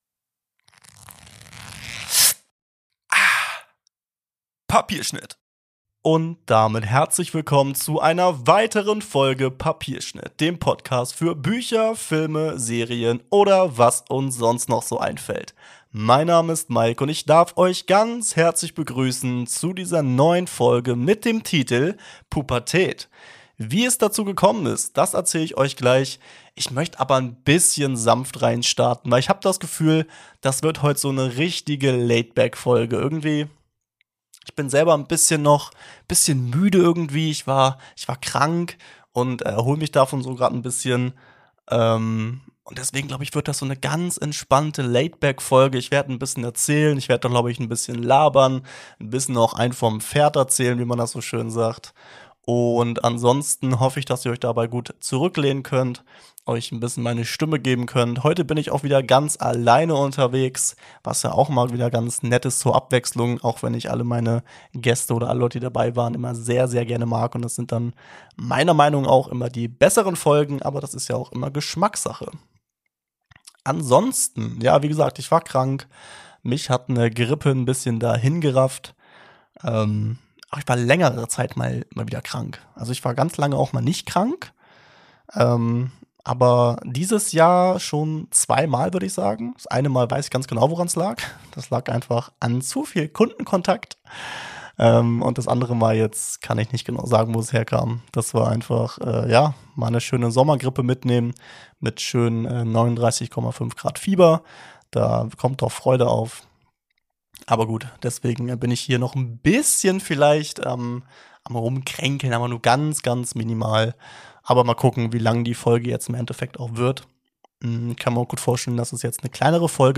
Keine Sorge :D Ich hoffe die monologisierte Folge gefällt Euch und wir hören uns dann nächste Woche wieder zu einer neuen Folge Papierschnitt.